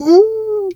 pgs/Assets/Audio/Animal_Impersonations/bear_pain_whimper_05.wav at master
bear_pain_whimper_05.wav